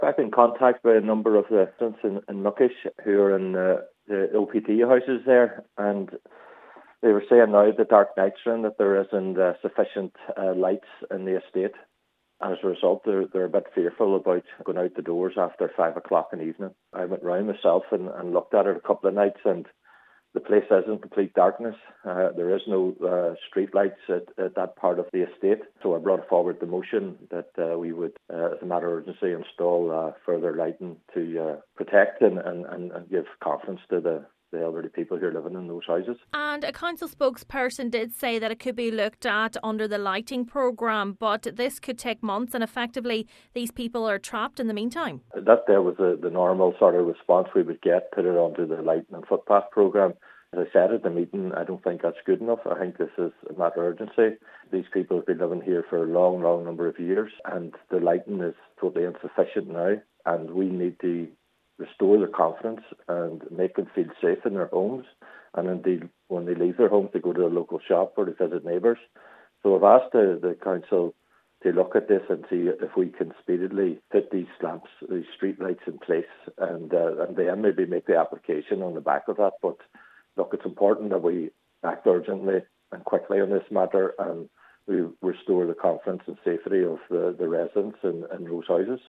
It’s led to calls from Councillor Gerry McMonagle on Donegal County Council to install additional lighting as a matter of urgency: